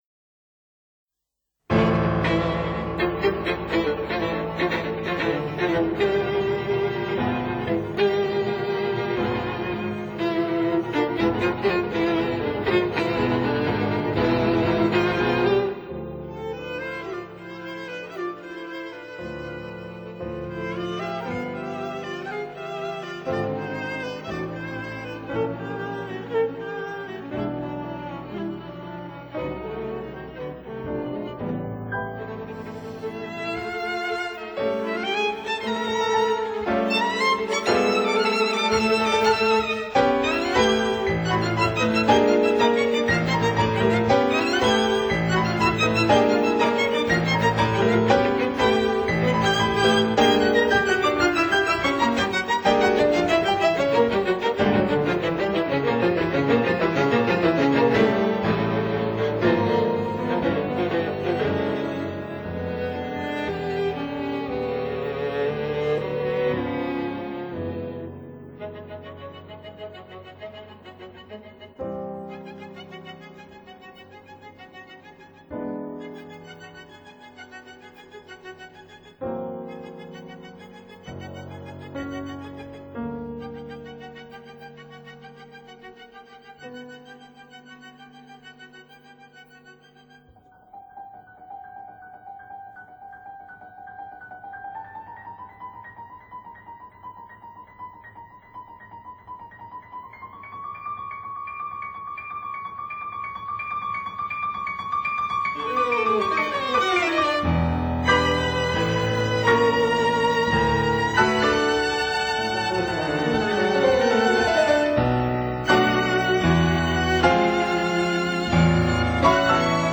Piano Quintet in D major
violin
viola
cello